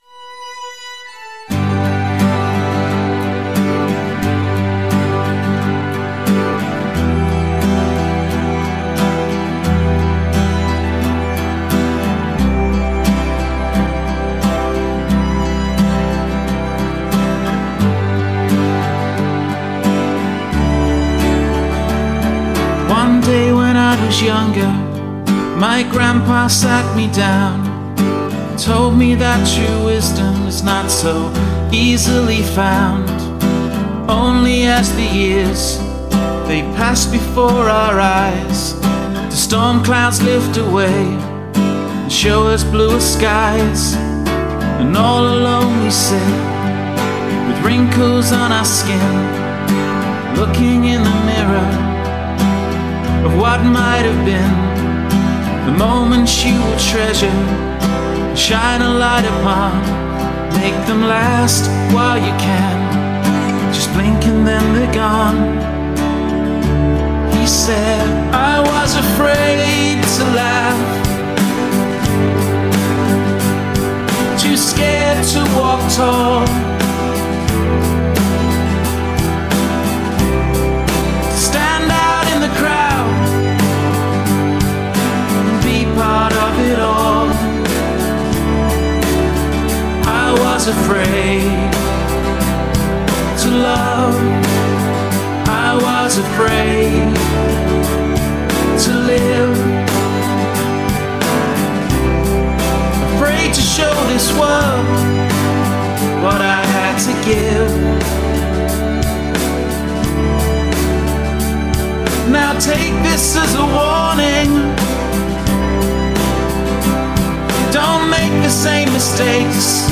The upbeat chorus is a nice change.